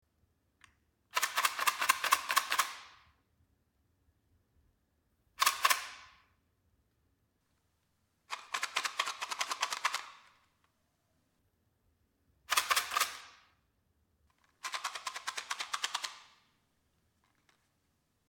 Коробок со спичками трясут
Коробок спичек подвергают встряхиванию
Тряска коробка с зажигательными палочками